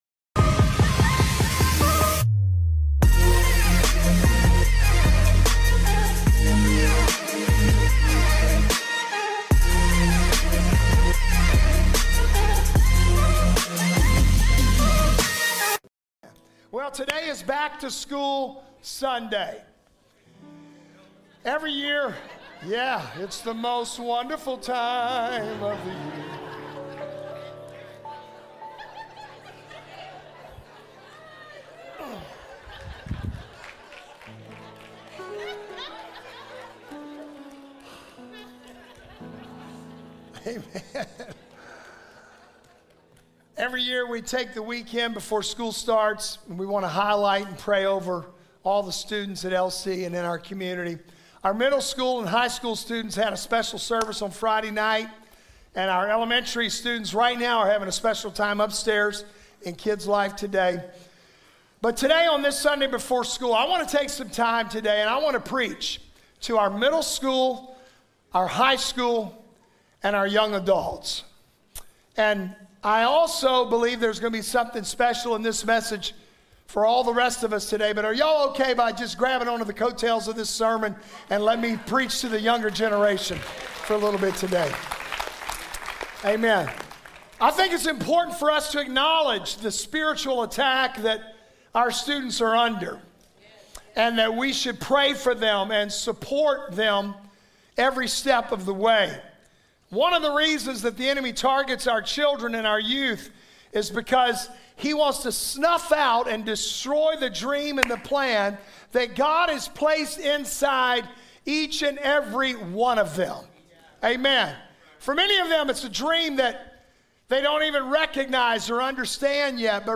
The sermon emphasizes that despite the world's wickedness or our personal failures, God's purpose for us remains steadfast. We're challenged to embrace our role as a 'chosen generation' (1 Peter 2:9), not letting our youth or inexperience hold us back.